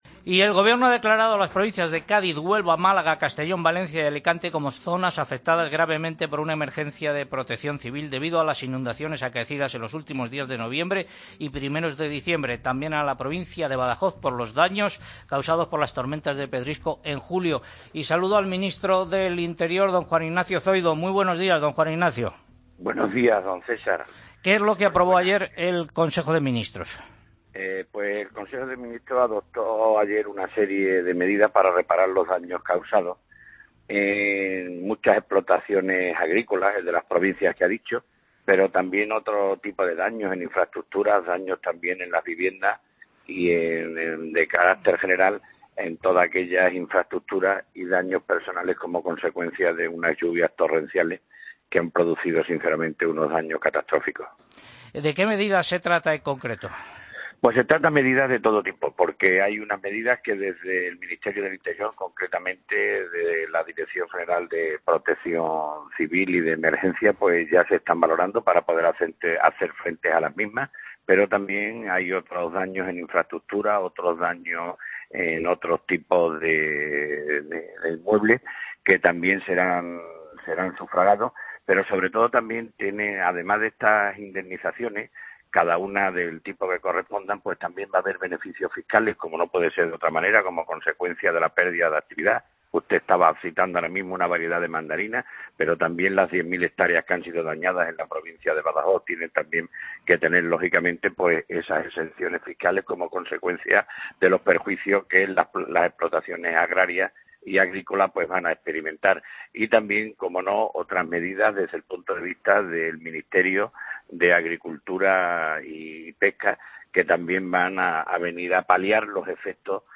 Escucha la entrevista Juan Ignacio Zoido, ministro del Interior, en Agropopular